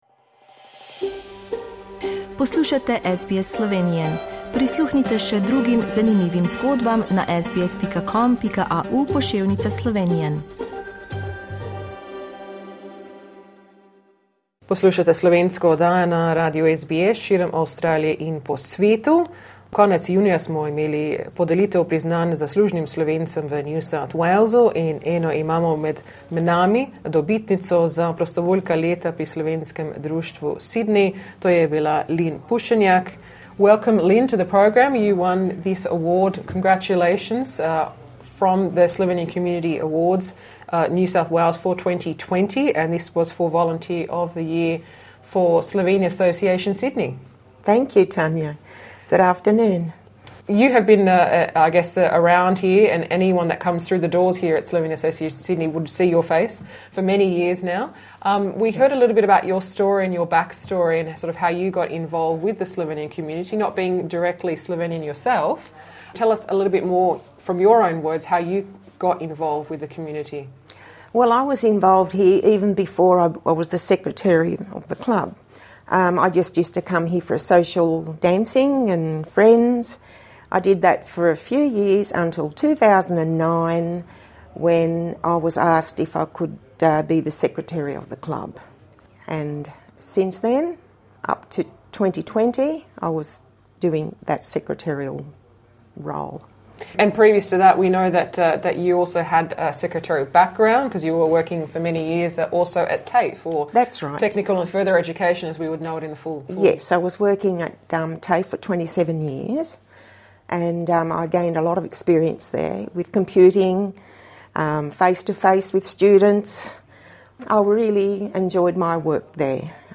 Z njo smo se pogovarjali po podelitvi.